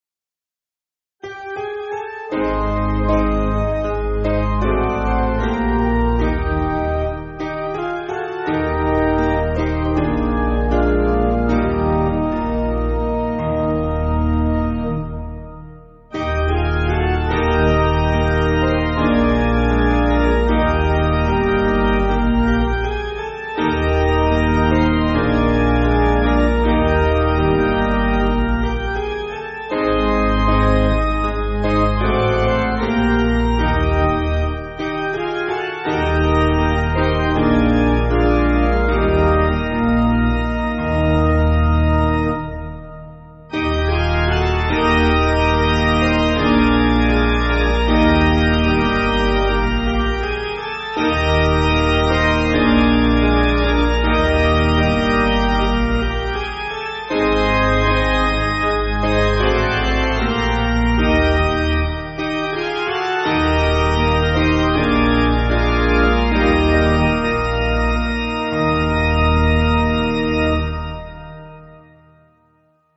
Basic Piano & Organ
(CM)   2/Eb